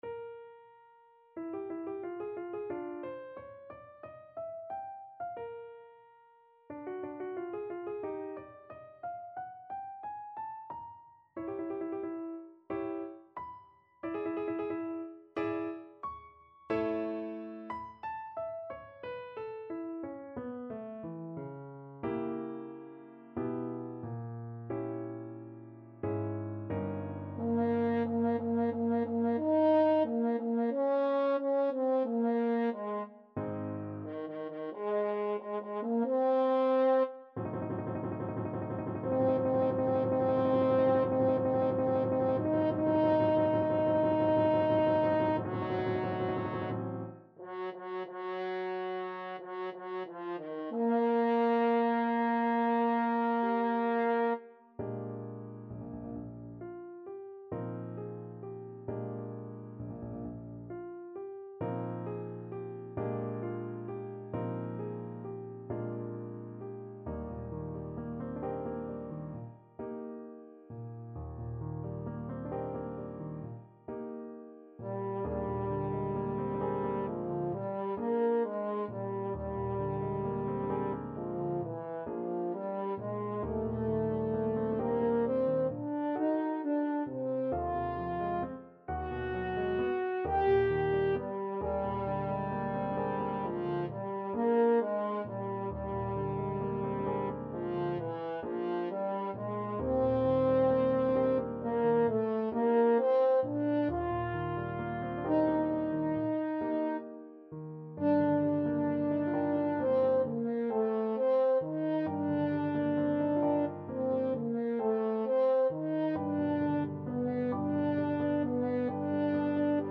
Moderato =90
4/4 (View more 4/4 Music)
Classical (View more Classical French Horn Music)